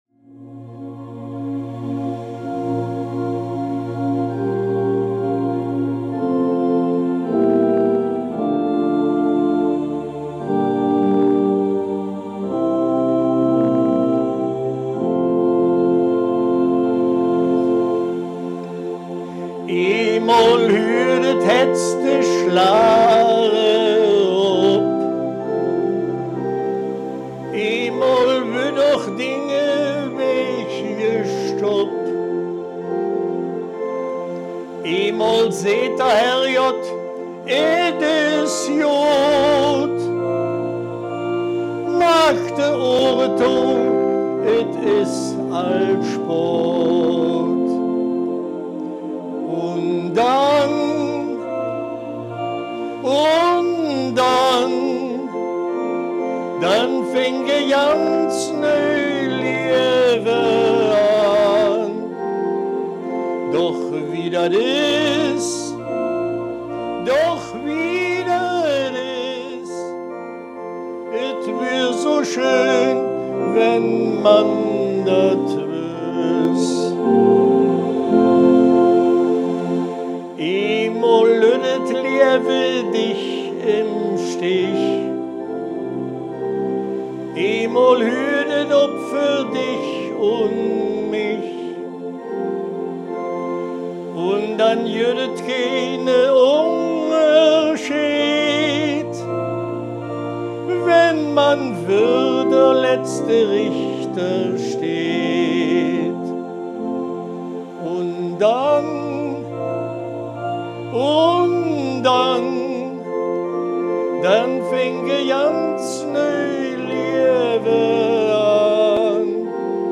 Baaler Riedelland - Erkelenzer Börde
Liedtext